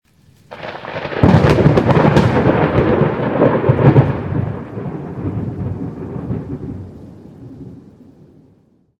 thunder_22.ogg